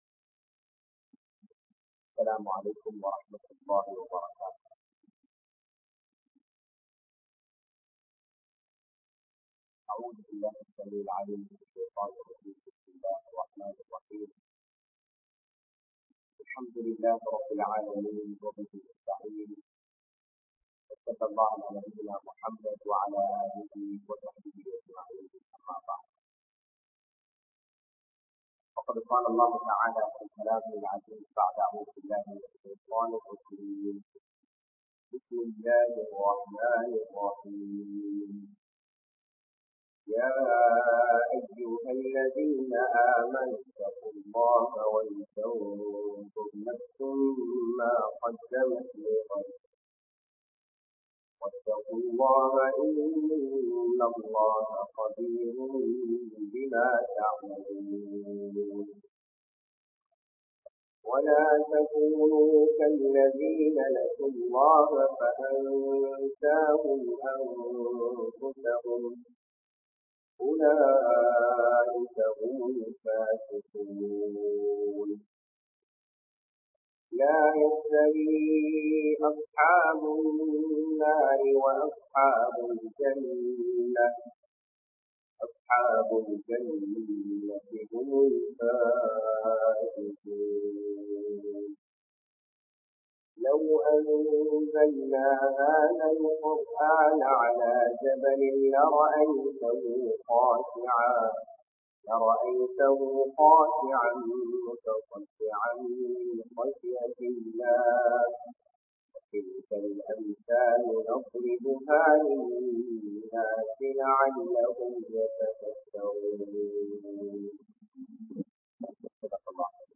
Islaaththai Neasiungal (இஸ்லாத்தை நேசியுங்கள்) | Audio Bayans | All Ceylon Muslim Youth Community | Addalaichenai